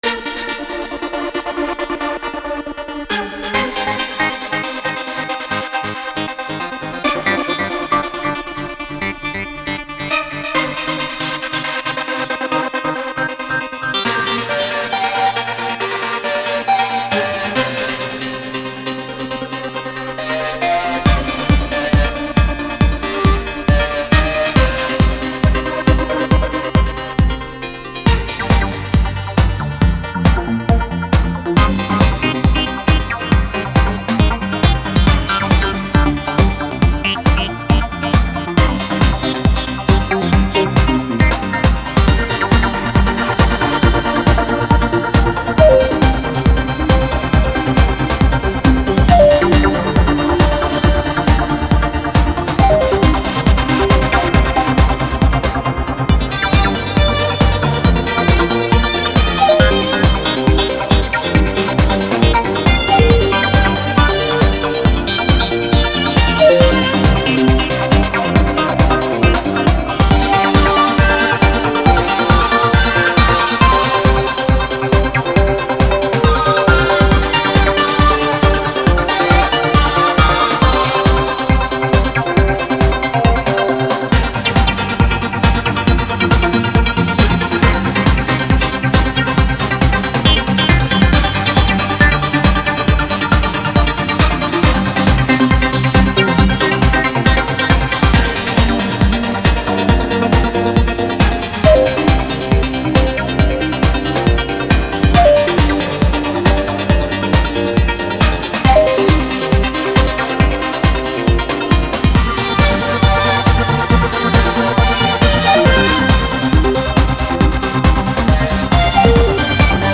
dance/electronic
Techno
Trance
Electro